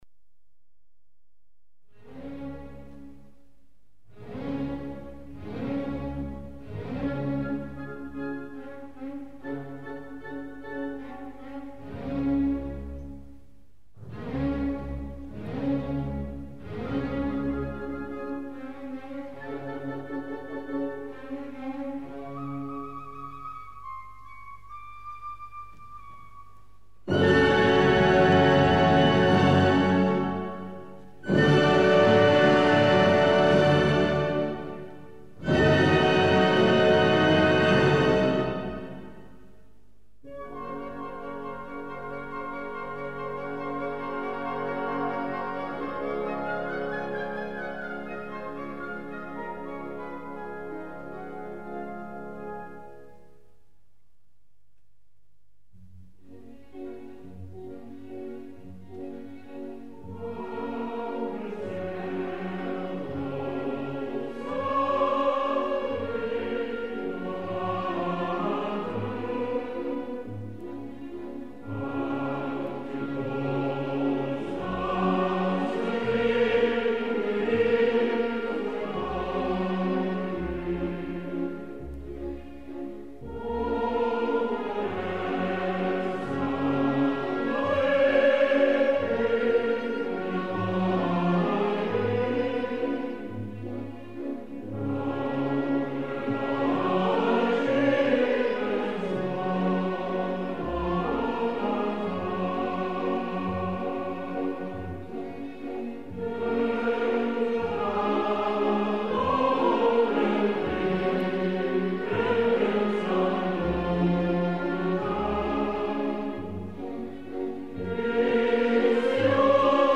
Per acabar, us deixem aquí els Cors de “Nabucco” de Giuseppe Verdi.